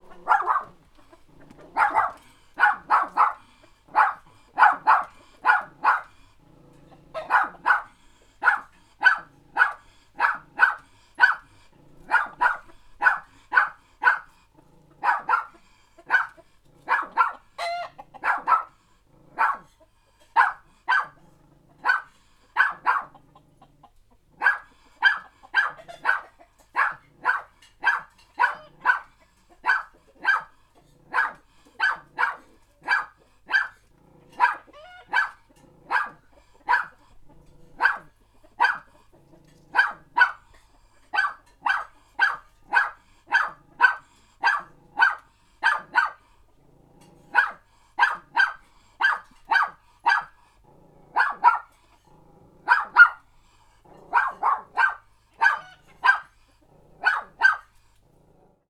Small dog barking.wav